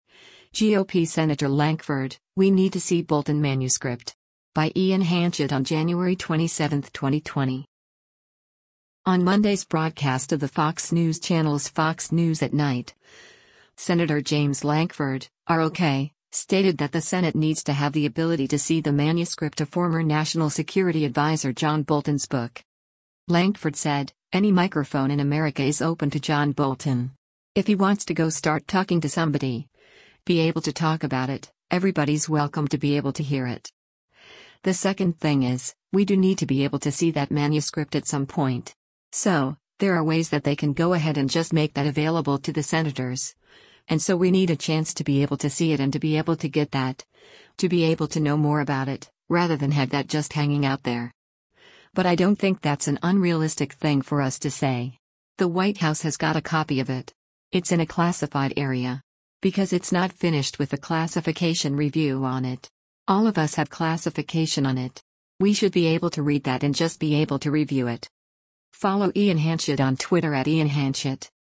On Monday’s broadcast of the Fox News Channel’s “Fox News @ Night,” Sen. James Lankford (R-OK) stated that the Senate needs to have the ability to see the manuscript of former National Security Adviser John Bolton’s book.